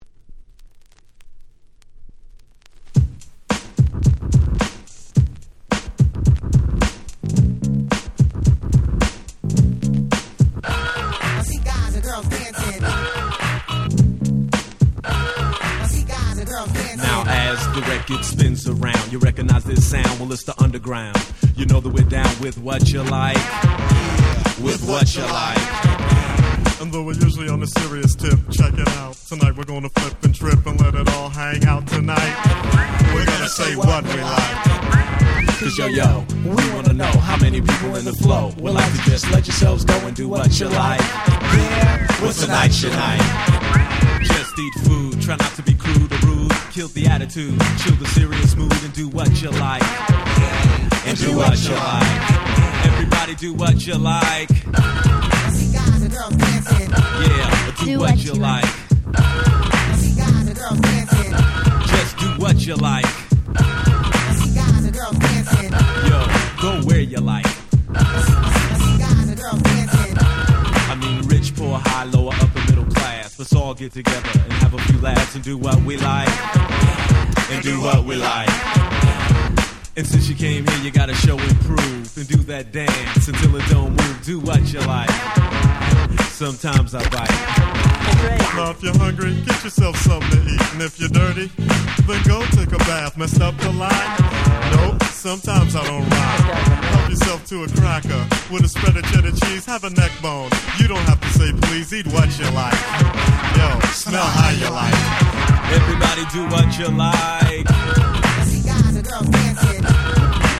89' Smash Hit Hip Hop !!
楽しくFunkyな1曲！